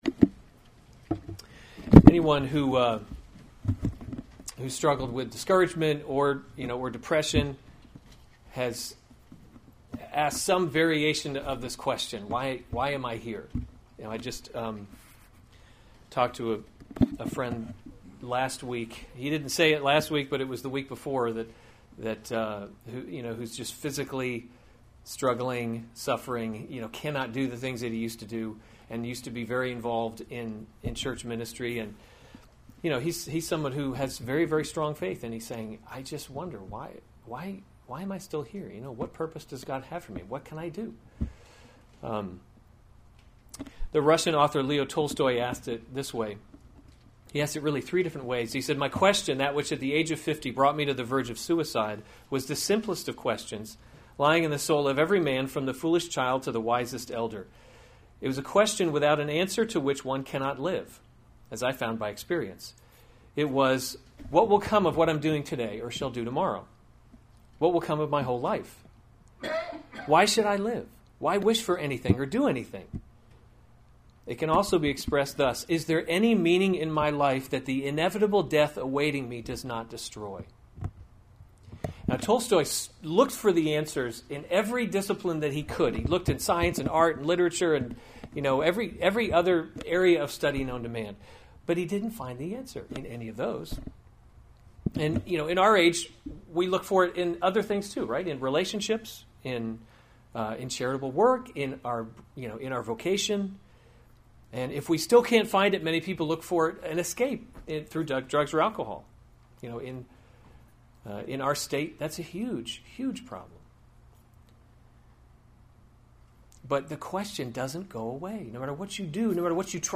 July 16, 2016 Psalms – Summer Series series Weekly Sunday Service Save/Download this sermon Psalm 96 Other sermons from Psalm Worship in the Splendor of Holiness 96:1 Oh sing to the […]